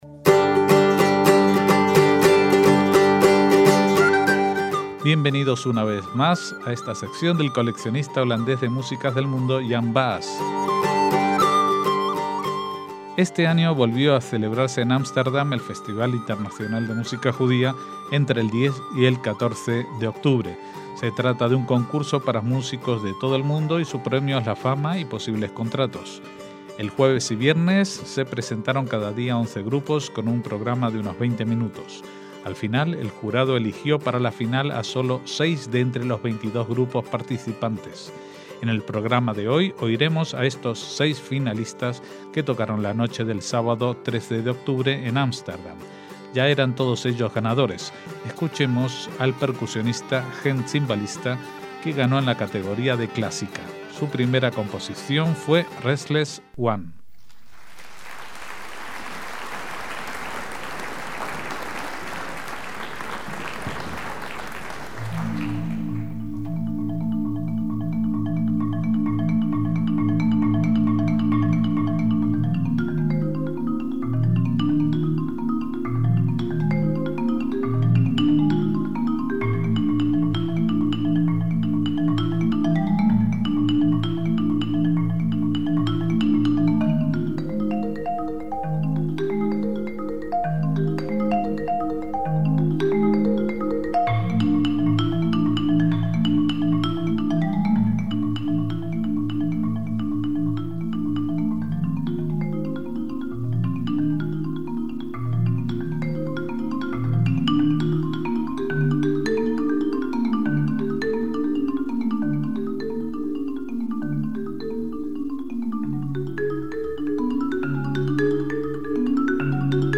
celebrado en Ámsterdam en 2012 podemos oír a los seis finalistas